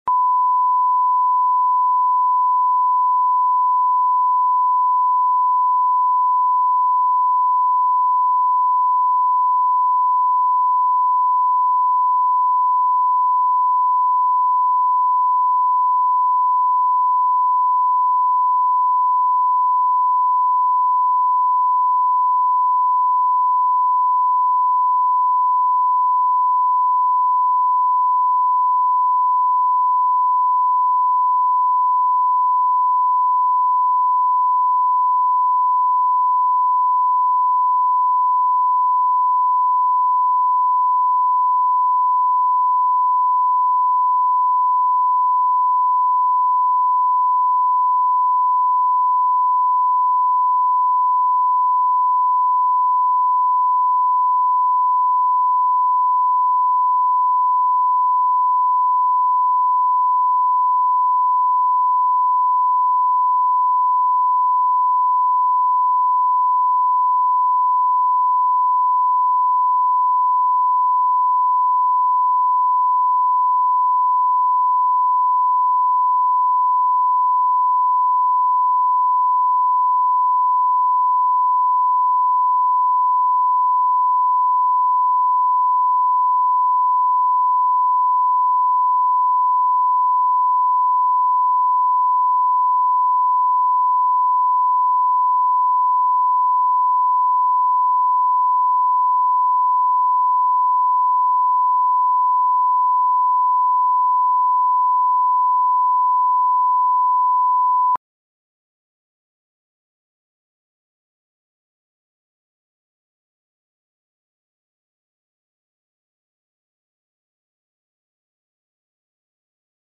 Аудиокнига Блеск и нищета